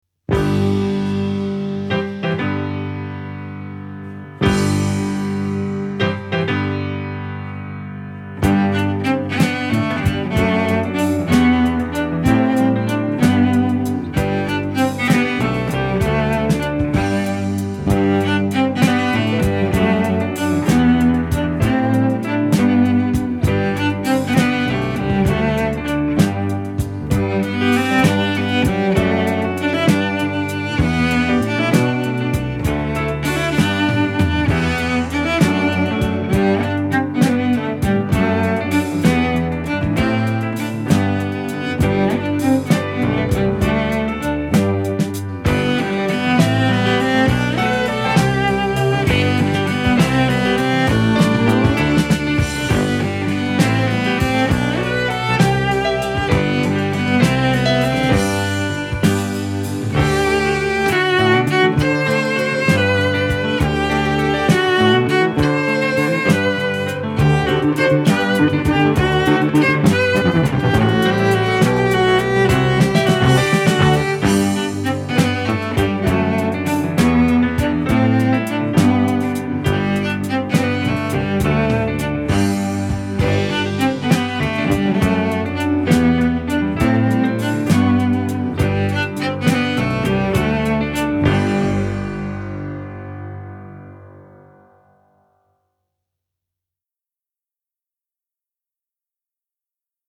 Folk Music
христианской песенной группой